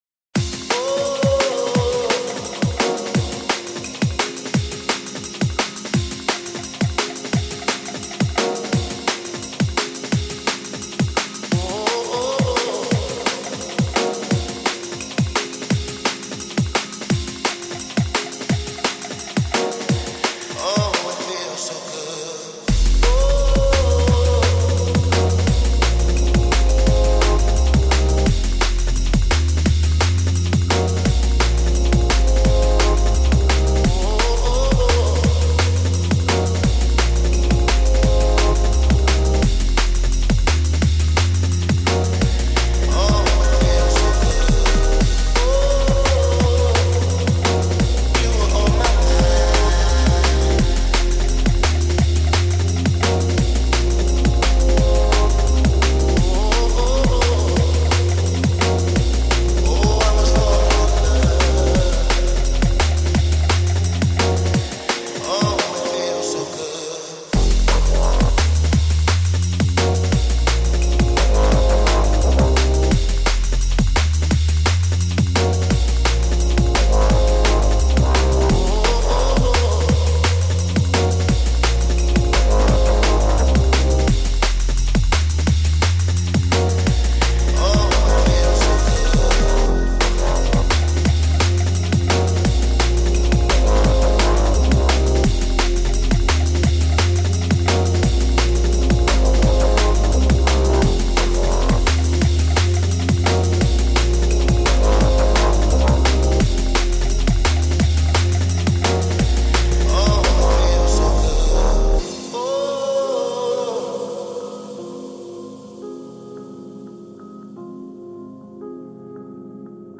Drum and Bass / Jungle